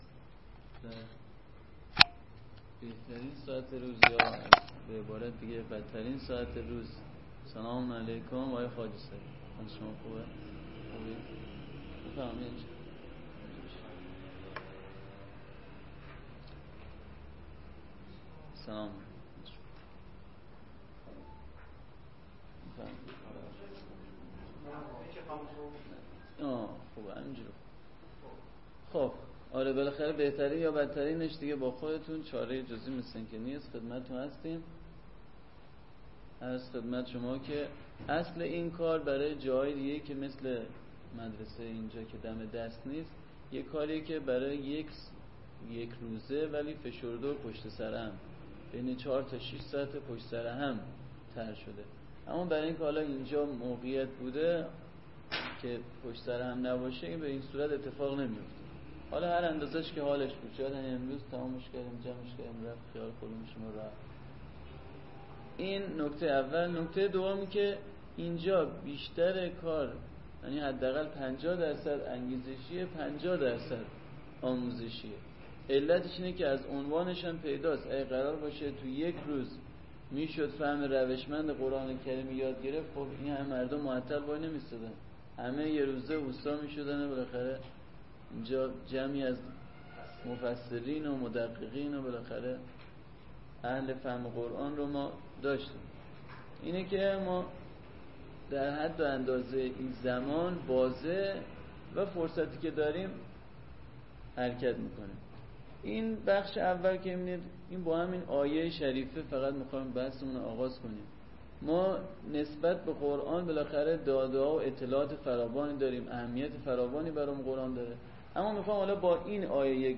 بنا به اهمیت بحث و با توجه به‌پیش رو بودن ایام ماه مبارک رمضان که بهار قرآن است؛ فایل‌های صوتی این کارگاه در اختیار کلیه مؤمنین به‌ویژه طلاب علوم دینی، قرآن‌پژوهان و سایر دانش‌پژوهان قرار می‌گیرد.